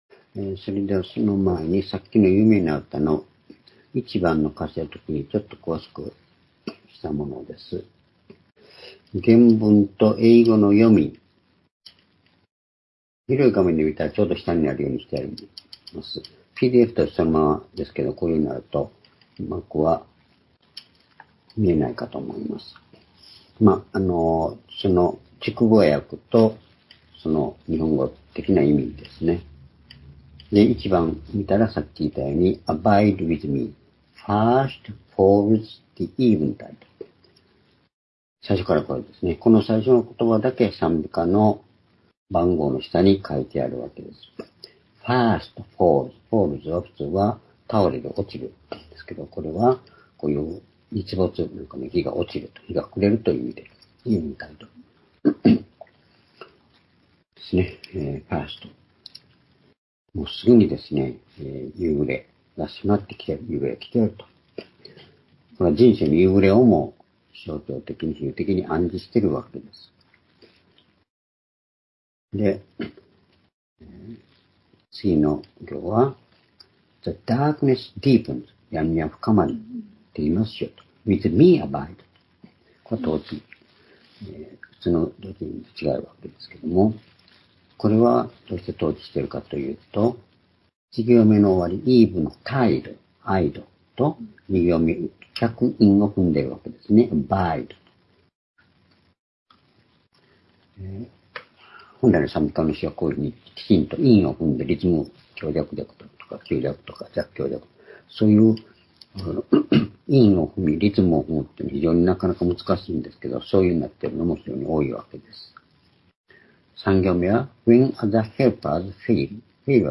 （主日・夕拝）礼拝日時 2024年6月18日(夕拝) 聖書講話箇所 「闇のただなかで与えられる慈しみ、讃美、祈り」 詩編42編9節～12節 ※視聴できない場合は をクリックしてください。